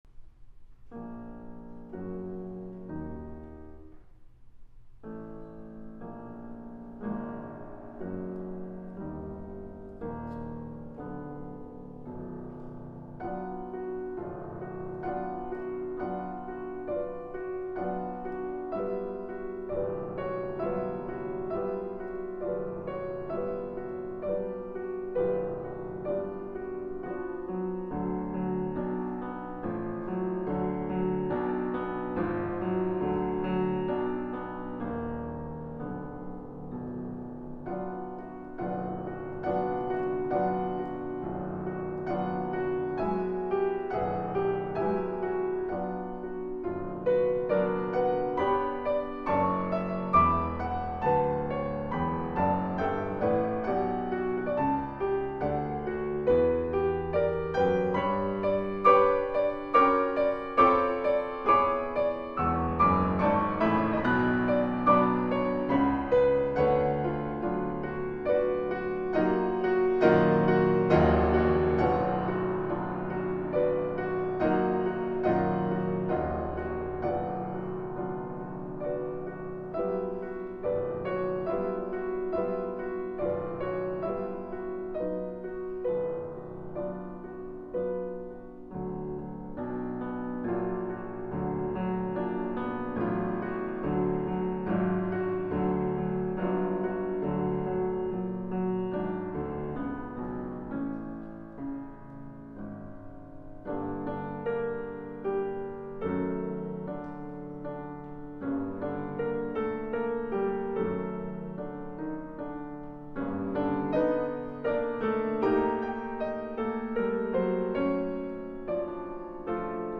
Concert Live recording